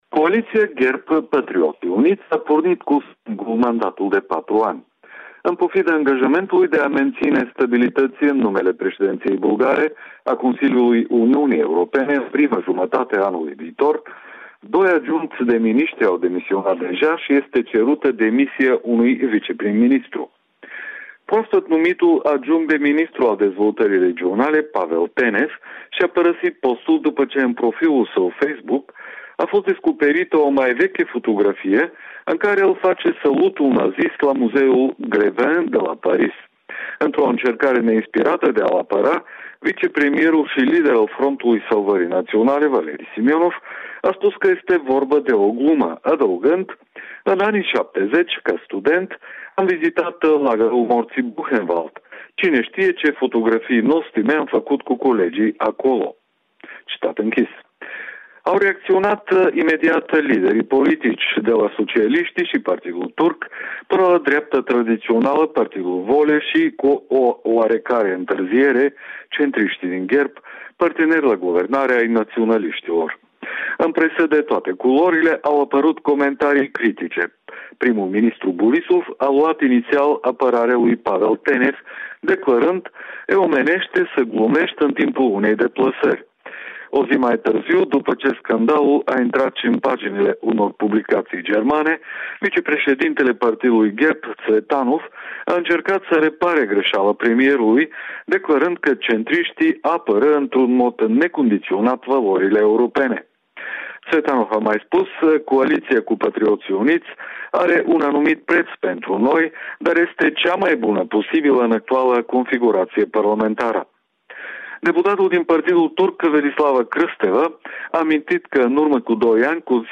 Corespondența zilei de la Sofia